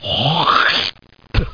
1 channel
spit.mp3